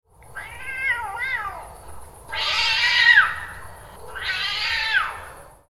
Aggressive Cat Growl With Sudden Screech Sound Effect
Two male cats confront each other in a backyard and start growling and hissing in an aggressive standoff. Cat sounds.
Aggressive-cat-growl-with-sudden-screech-sound-effect.mp3